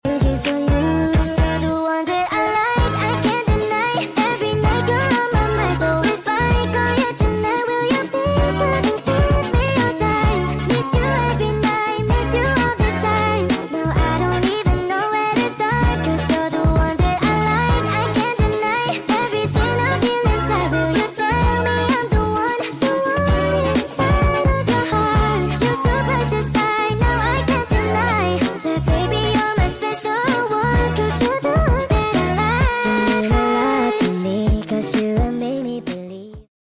Baekhyun Interview: The Puppy🐶 Released